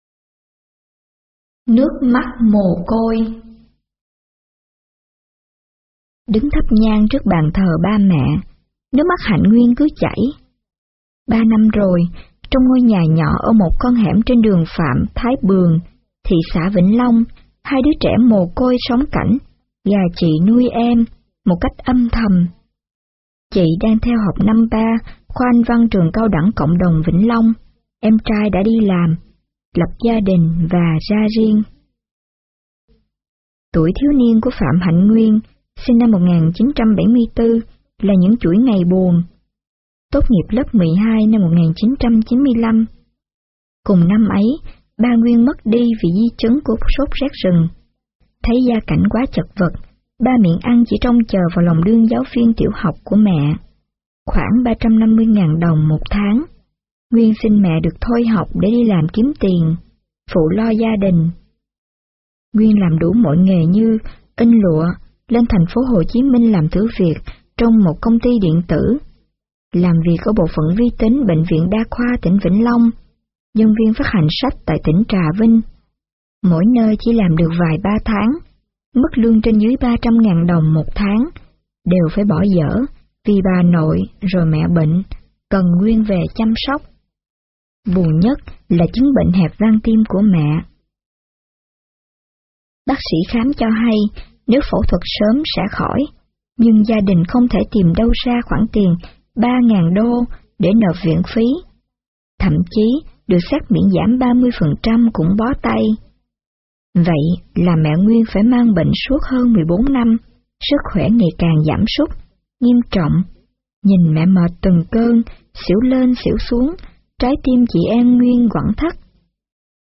Sách nói | Nước mắt mồ côi